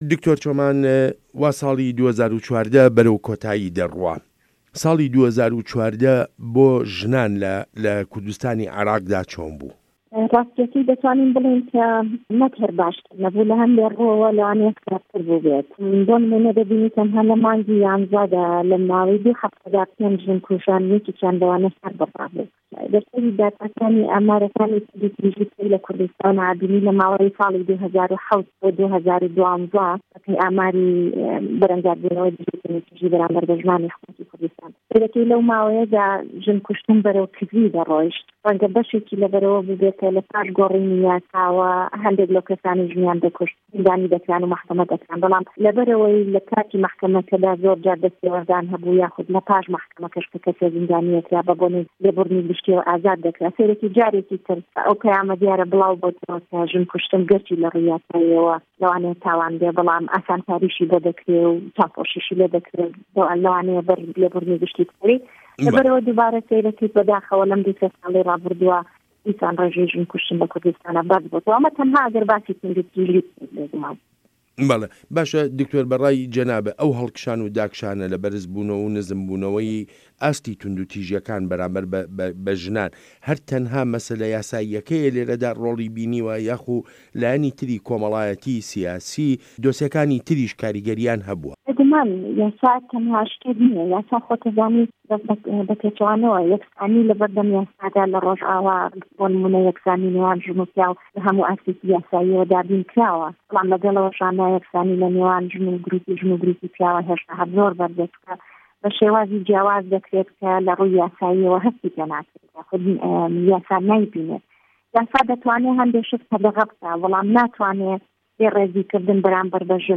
وتووێژ له‌گه‌ڵ دکتۆر چۆمان هه‌ردی